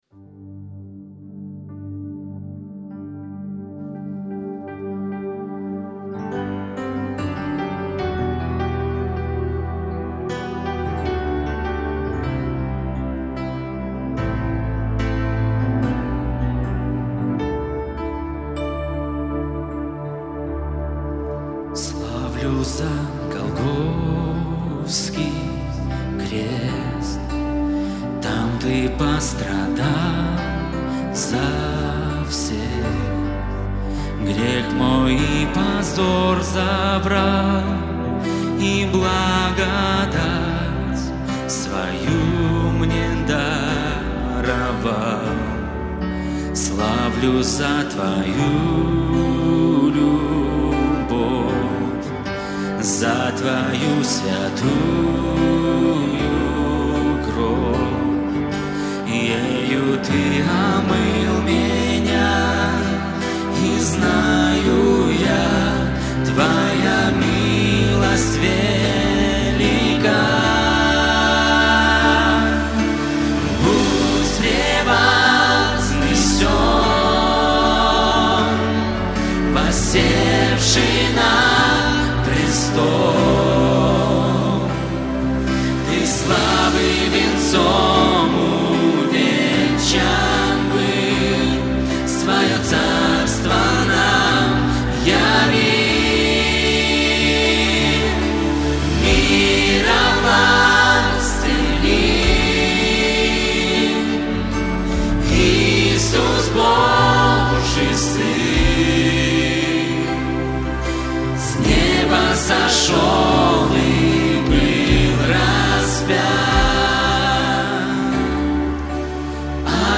Богослужение (ВС, вечер) - 5 октября 2025
Псалом